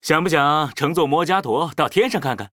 文件 文件历史 文件用途 全域文件用途 Kagon_amb_01.ogg （Ogg Vorbis声音文件，长度2.5秒，111 kbps，文件大小：34 KB） 源地址:地下城与勇士游戏语音 文件历史 点击某个日期/时间查看对应时刻的文件。 日期/时间 缩略图 大小 用户 备注 当前 2018年5月13日 (日) 02:14 2.5秒 （34 KB） 地下城与勇士  （ 留言 | 贡献 ） 分类:卡坤 分类:地下城与勇士 源地址:地下城与勇士游戏语音 您不可以覆盖此文件。